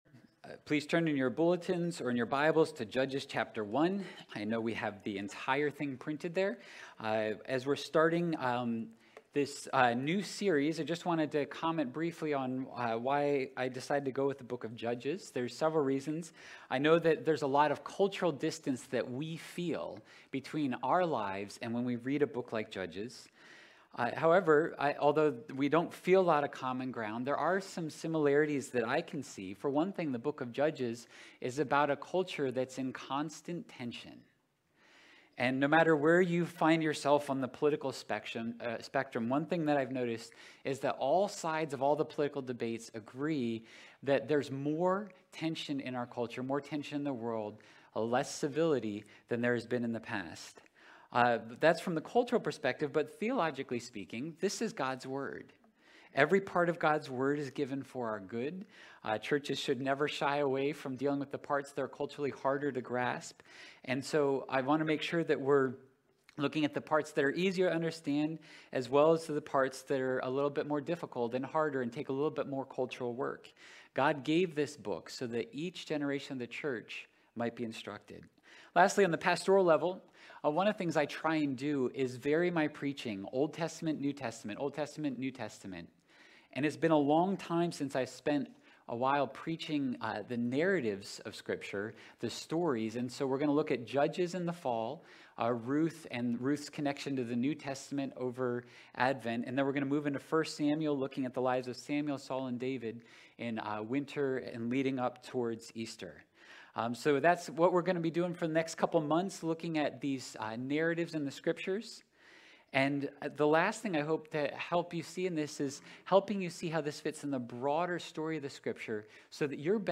Sunday Worship – September 11 of 2022 – A Pattern of Disobedience, a Day of Weeping, and a Story of Hope